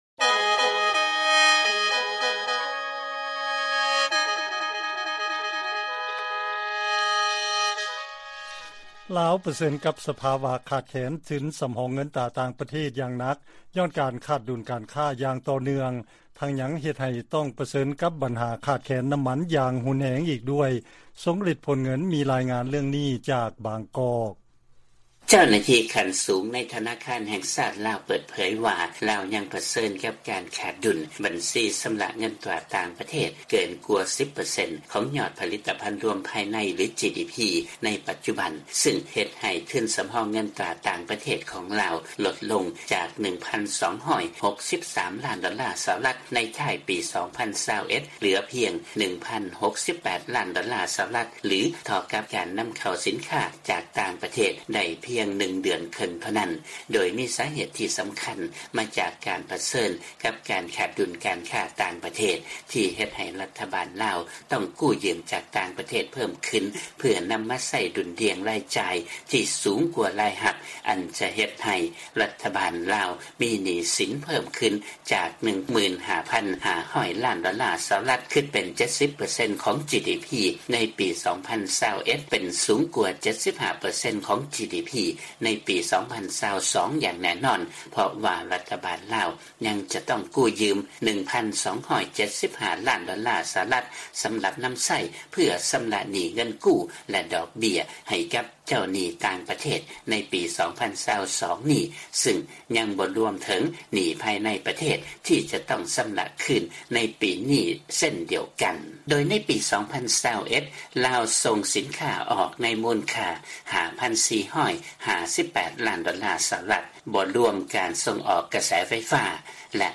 ເຊີນຟັງລາຍງານກ່ຽວກັບການຂາດແຄນເງິນຕາຕ່າງປະເທດເພື່ອຊື້ສິນຄ້າຈາກຕ່າງປະເທດເຂົ້າມາລາວ